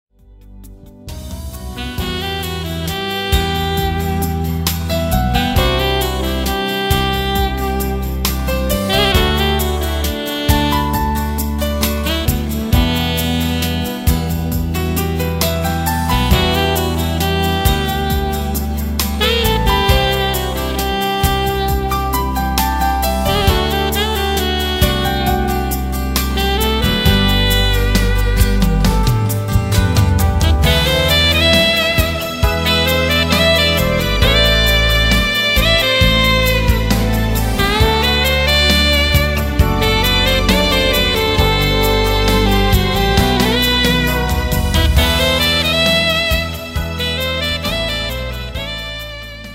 (SOPRANO, ALTO & TENOR SAXES)
(BASS)
(ACUSTIC & ELECTRIC GUITARS)
DRUM& PERCUSSION PROGRAMMING)